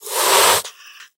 1.21.5 / assets / minecraft / sounds / mob / cat / hiss1.ogg
hiss1.ogg